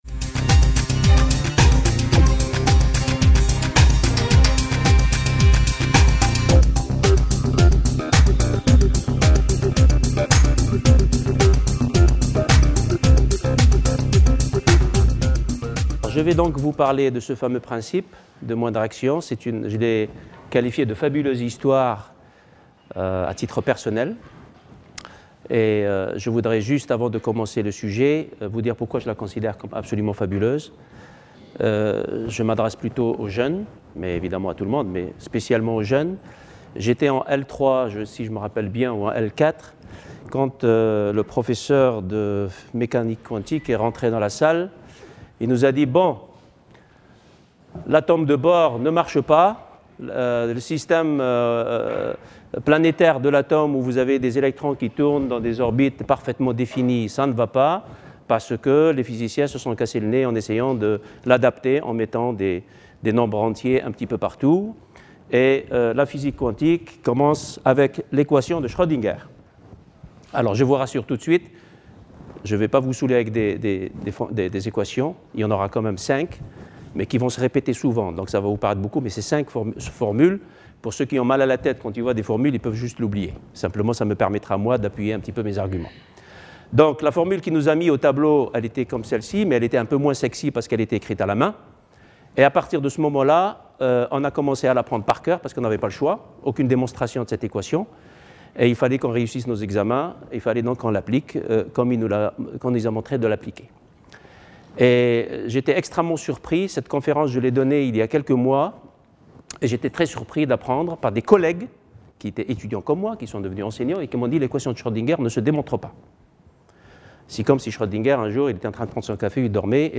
Aujourd'hui le principe de moindre action est à la base de la mécanique newtonienne, relativiste et quantique et touche même la biologie. Conférence grand public destinée aux esprits curieux !